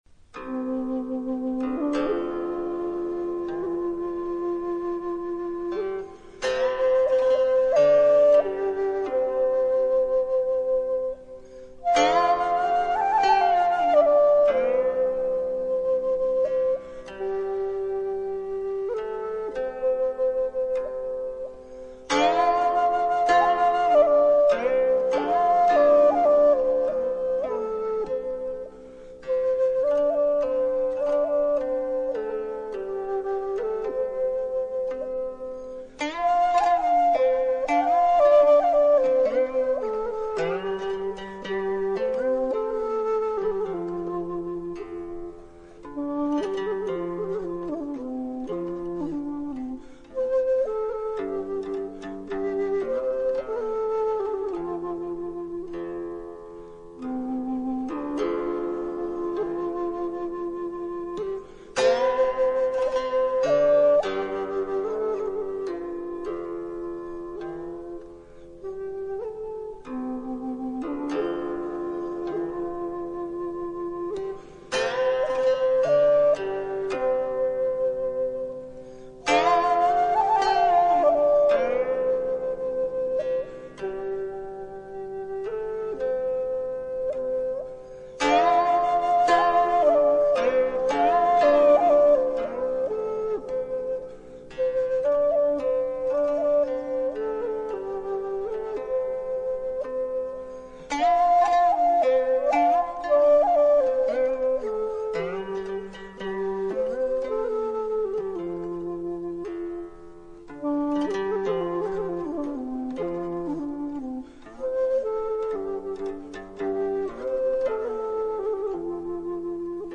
цитра цинь и флейта сяо F